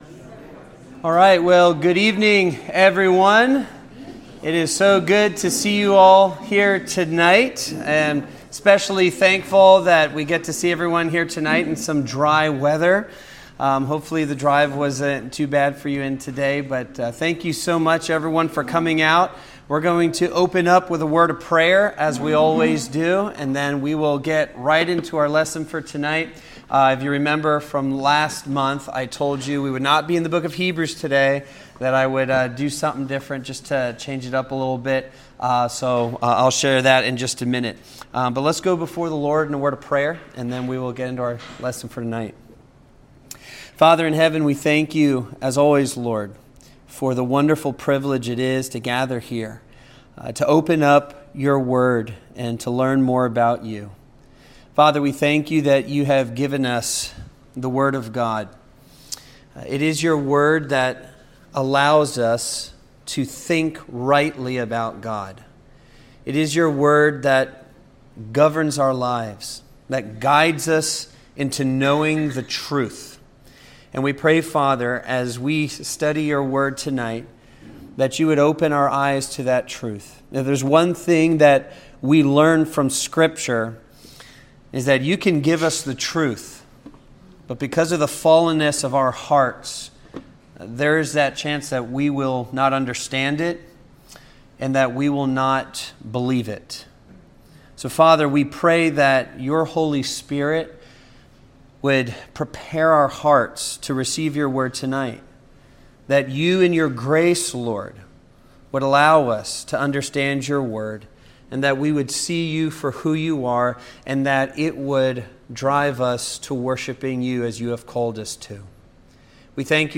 The Hive Bible Study - Psalm 66